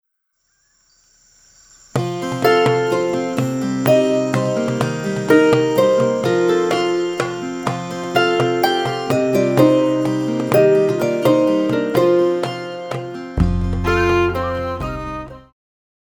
Pop
Viola
Band
Instrumental
World Music,Electronic Music
Only backing